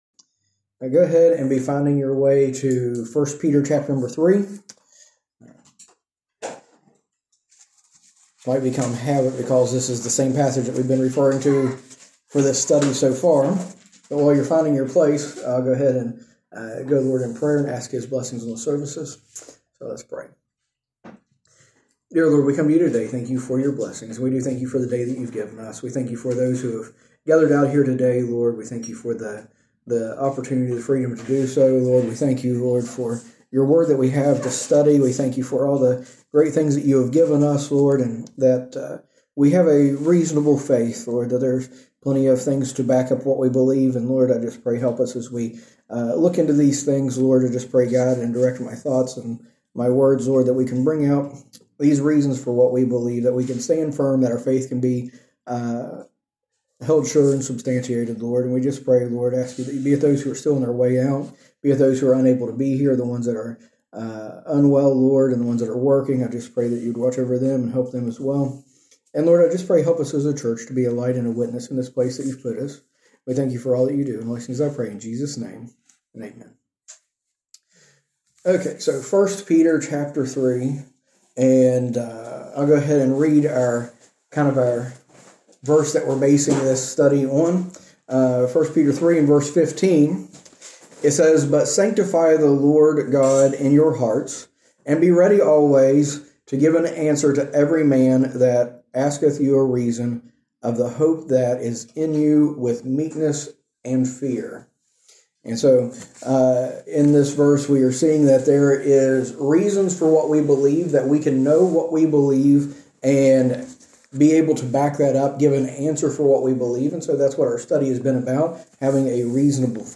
A message from the series "A Reasonable Faith."